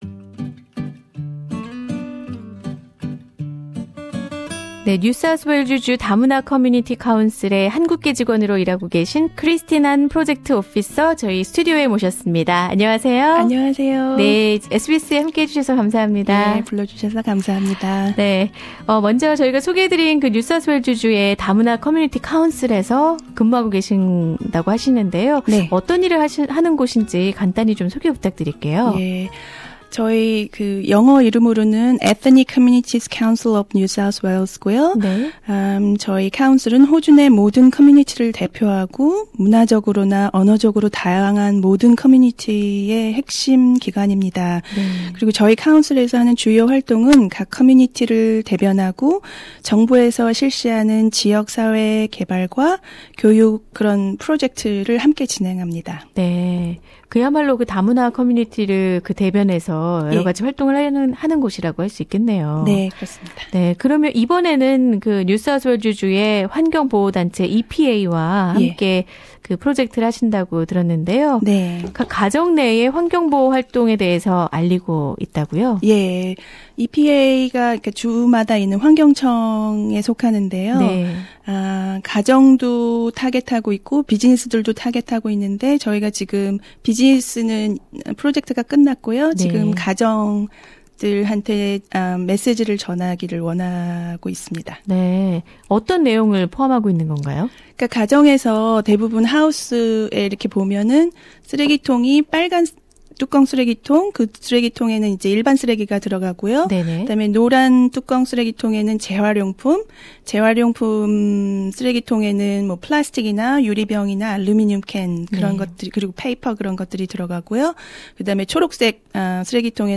[인터뷰] 화학폐기물 분리수거는 어떻게 하면 될까?